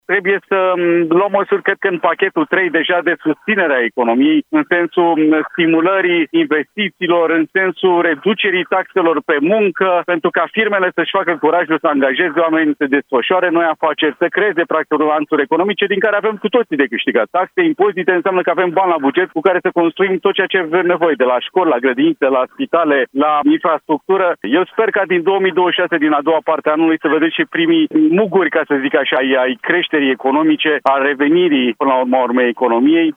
analist economic: „Eu sper ca din 2026, din a doua parte a anului, să vedem și primii muguri ai creșterii economice”